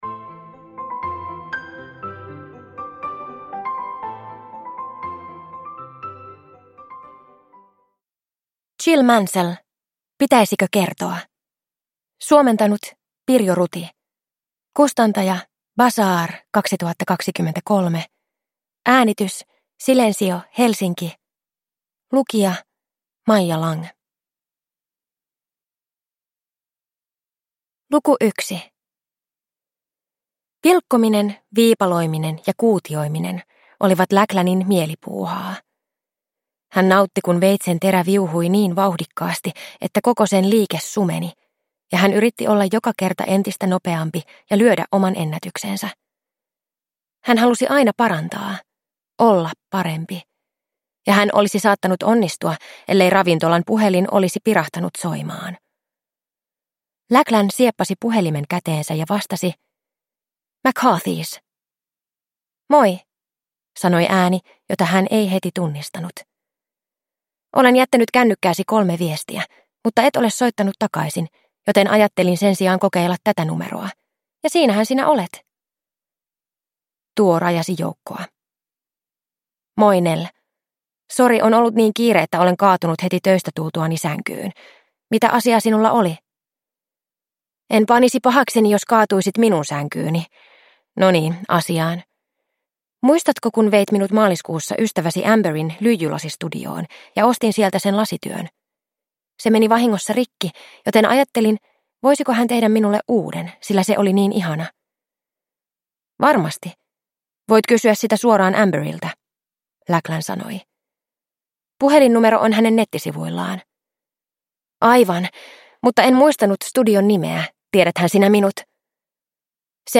Pitäisikö kertoa? – Ljudbok – Laddas ner